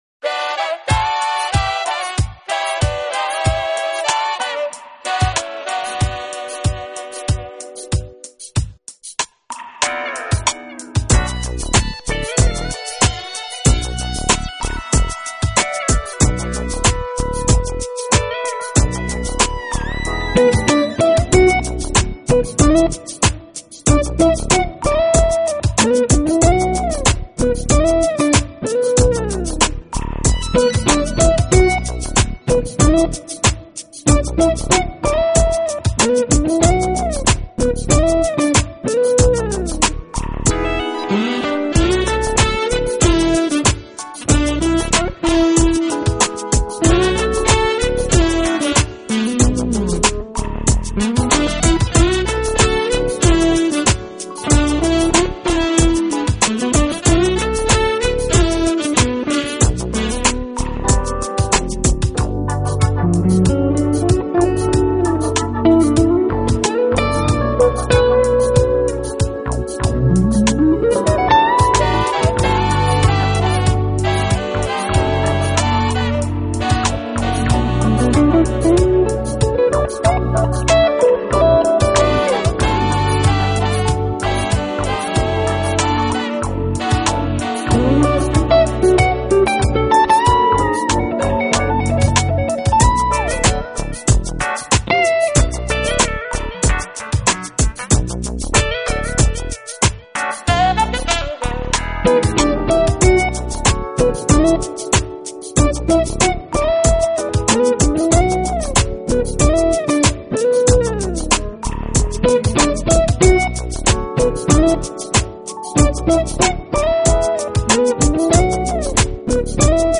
Genre: Jazz, Smooth Jazz, Instrumental